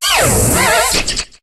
Cri de Scorvol dans Pokémon HOME.